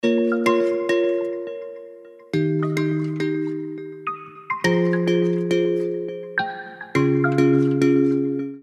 • Качество: 320, Stereo
спокойные
без слов
красивая мелодия
колокольчики
Приятная мелодия на уведомления